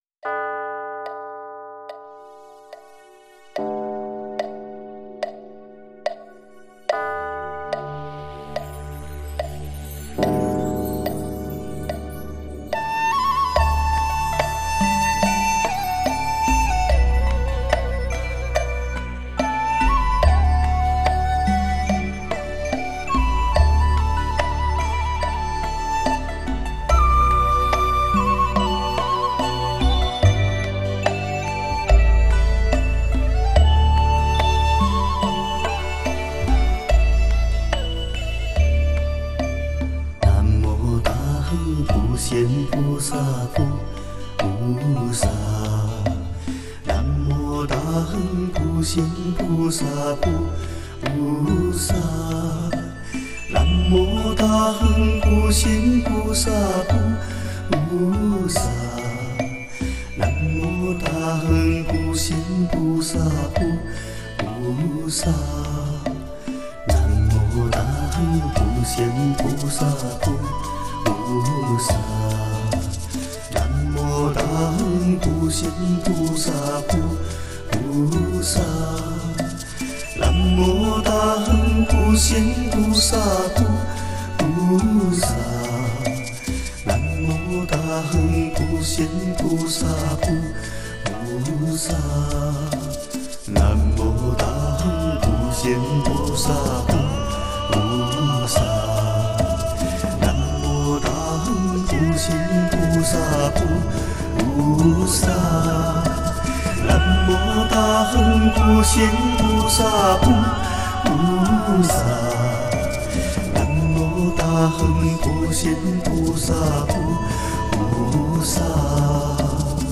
[14/1/2010]亲切崇敬感激的唱诵：南无大行普贤菩萨圣号